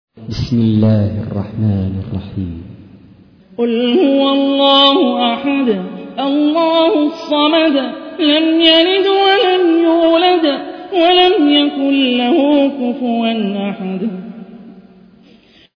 تحميل : 112. سورة الإخلاص / القارئ هاني الرفاعي / القرآن الكريم / موقع يا حسين